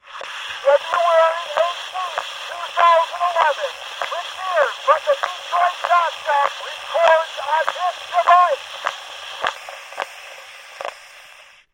Звук неразборчивого разговора с диктофона